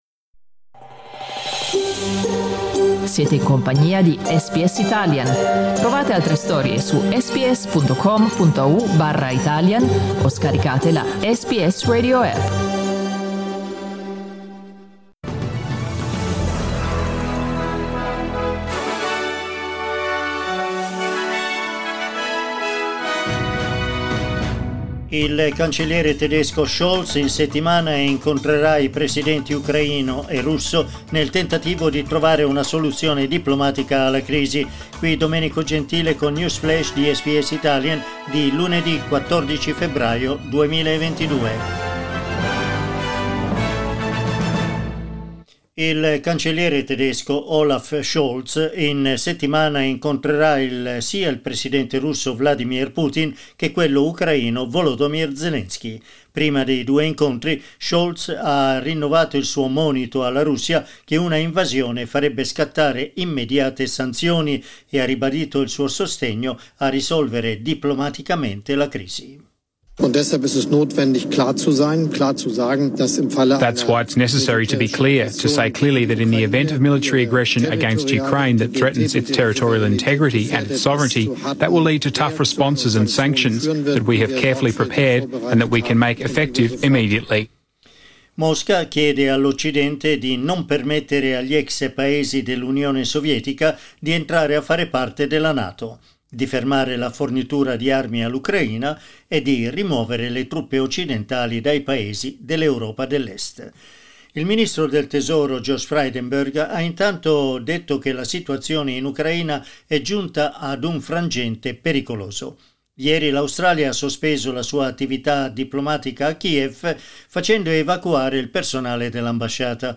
News flash lunedì 14 febbraio 2022
L'aggiornamento delle notizie di SBS Italian.